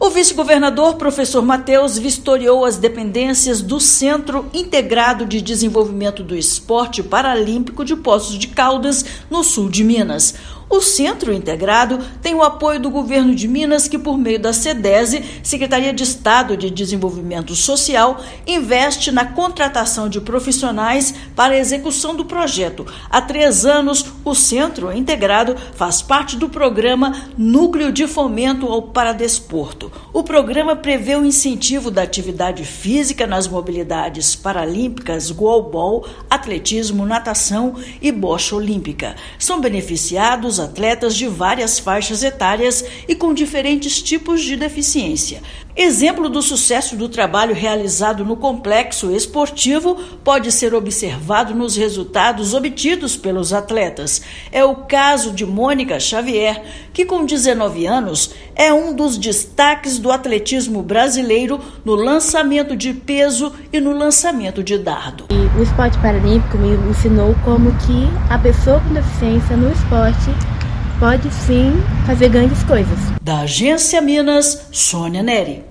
Complexo é responsável pela formação de atletas paralímpicos e conta com o apoio da Secretaria de Estado de Desenvolvimento Social (Sedese-MG). Ouça matéria de rádio.